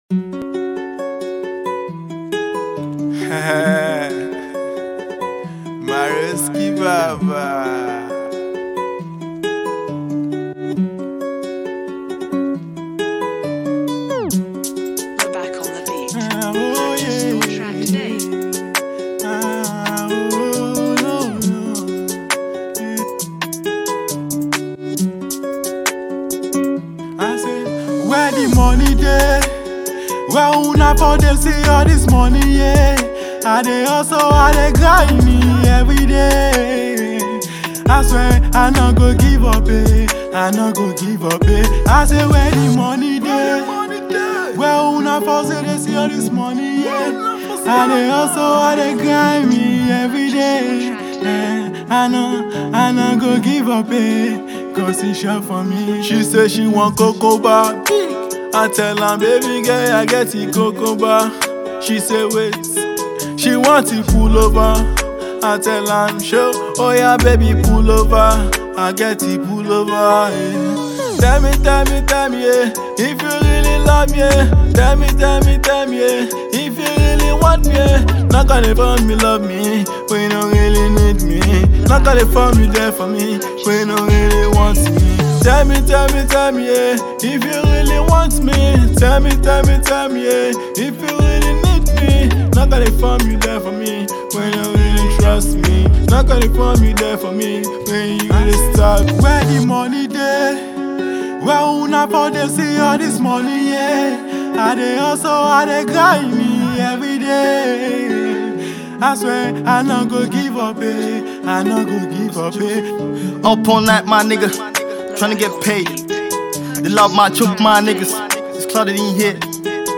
Recording and Performing Pop Artiste